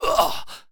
文件 文件历史 文件用途 全域文件用途 Enjo_dmg_01_3.ogg （Ogg Vorbis声音文件，长度0.7秒，135 kbps，文件大小：12 KB） 源地址:地下城与勇士游戏语音 文件历史 点击某个日期/时间查看对应时刻的文件。